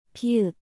(mi-eum) – M
It sounds like the English M. Appropriately, the shape of this consonant resembles an open mouth.